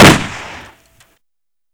pow_2.wav